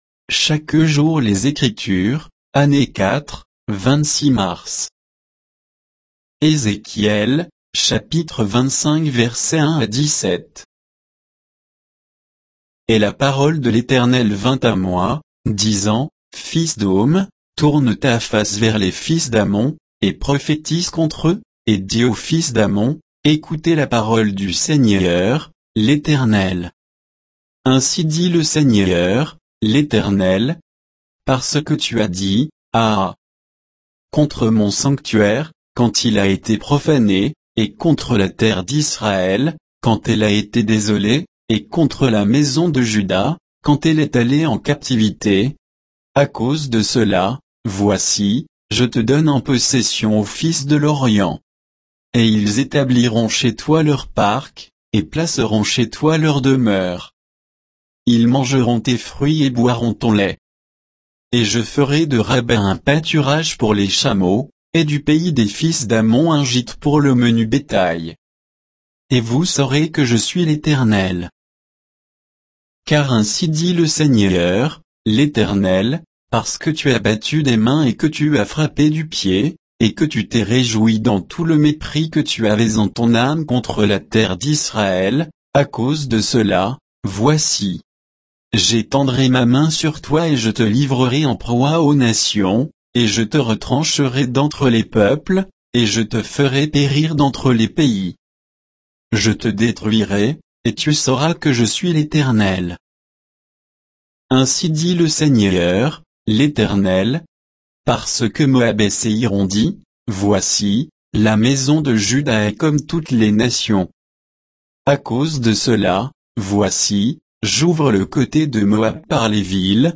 Méditation quoditienne de Chaque jour les Écritures sur Ézéchiel 25